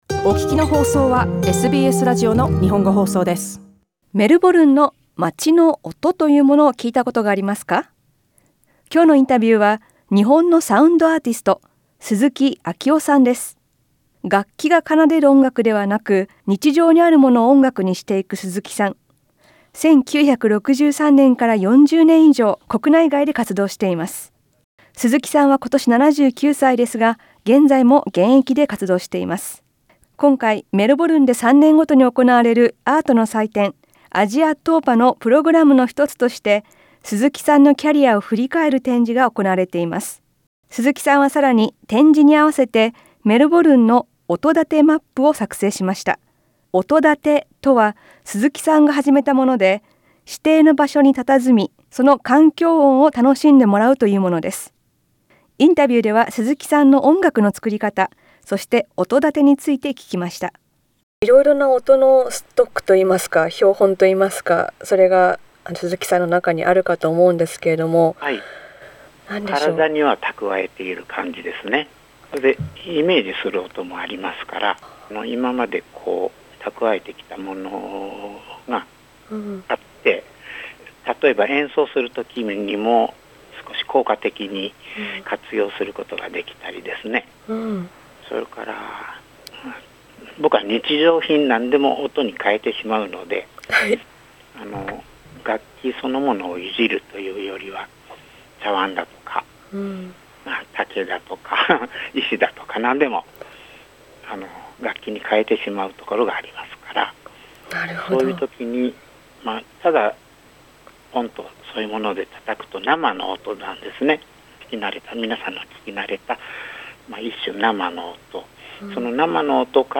インタビューでは、日常にあるものから作る音楽や、「点音」について聞きました。